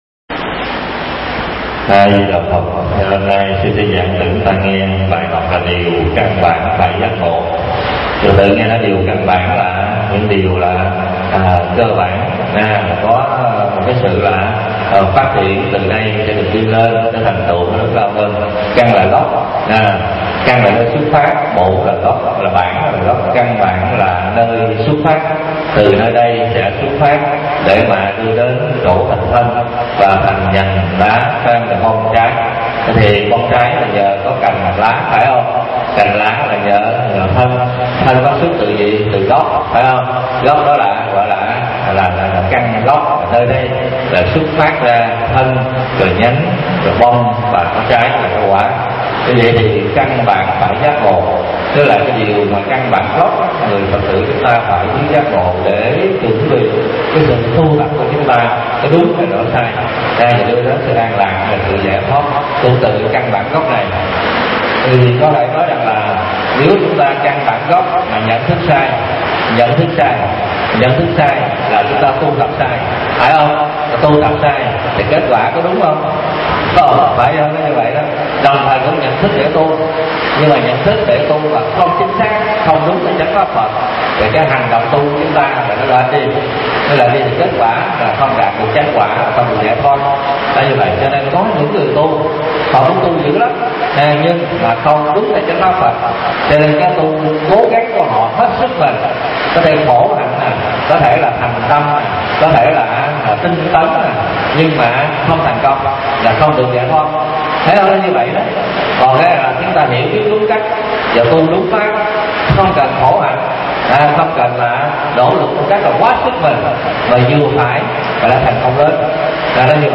Pháp Âm